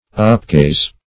Upgaze \Up*gaze"\